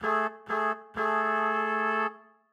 Index of /musicradar/gangster-sting-samples/95bpm Loops
GS_MuteHorn_95-A1.wav